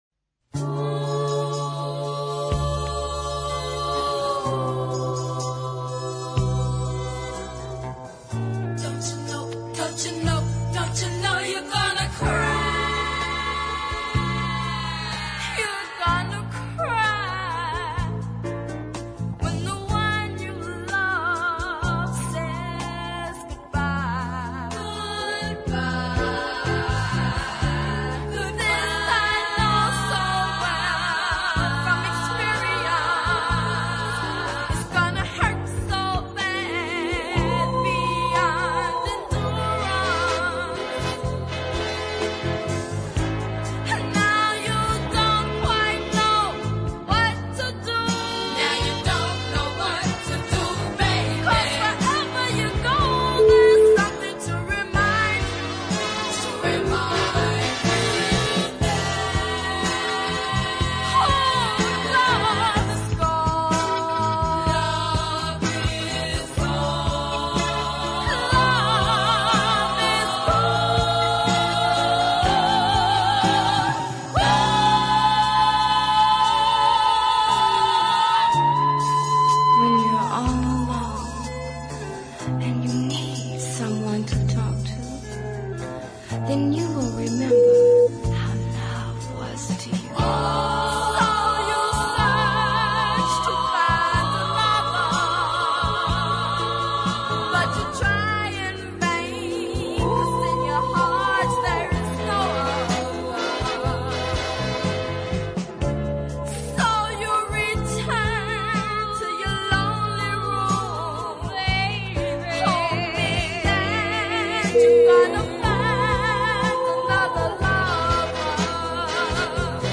a fine harmony ballad, nicely sung and well arranged.